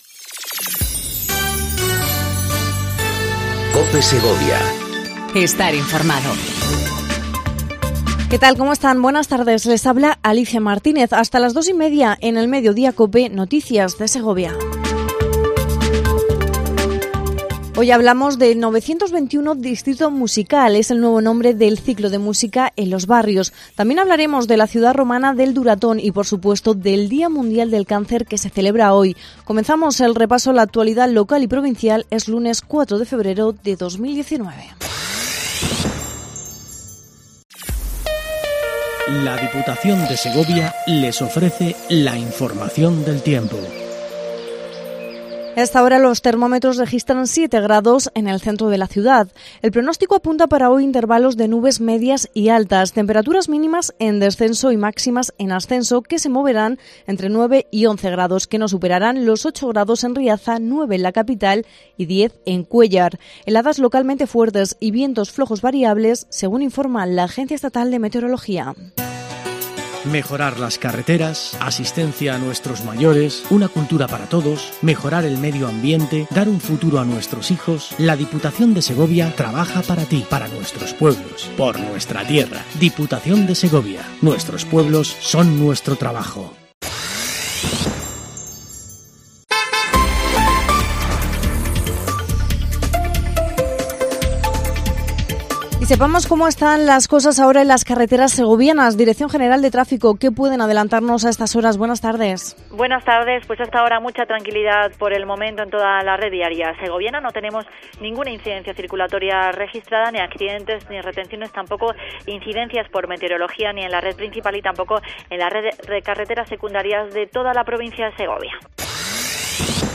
INFORMATIVO DEL MEDIODÍA EN COPE SEGOVIA 14:20 DEL 04/02/19